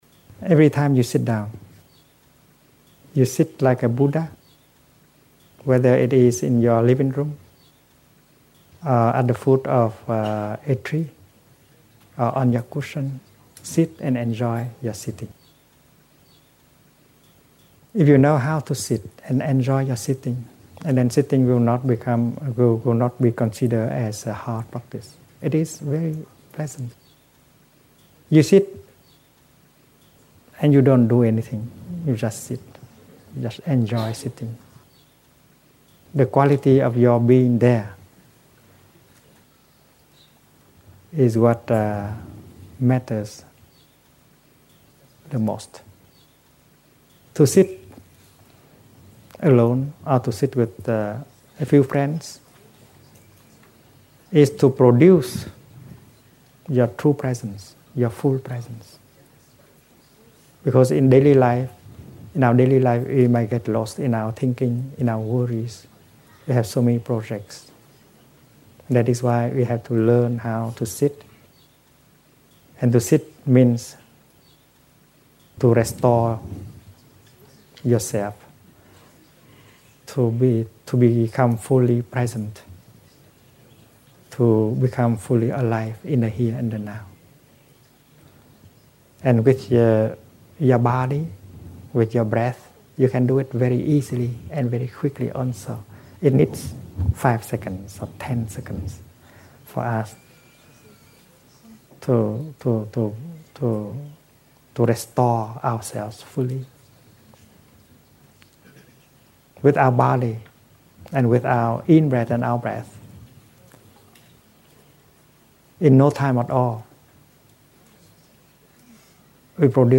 Thich Nhat Hanh explains the art of sitting.